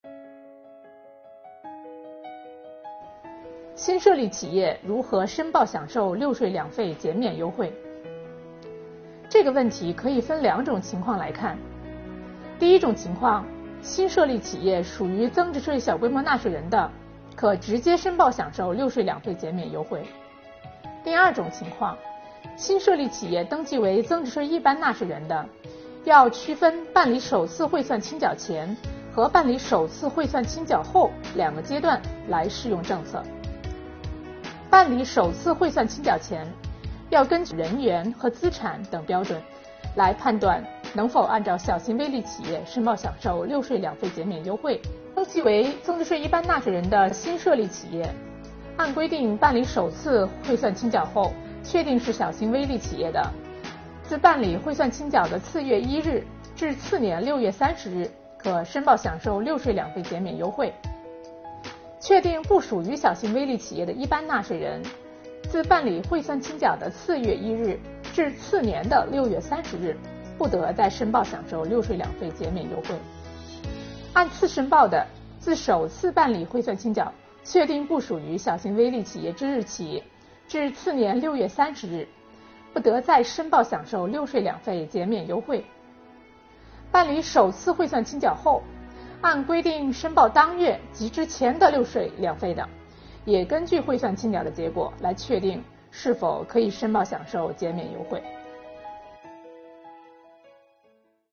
本期课程由国家税务总局财产和行为税司副司长刘宜担任主讲人，解读小微企业“六税两费”减免政策。